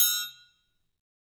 Triangle3-HitM_v2_rr1_Sum.wav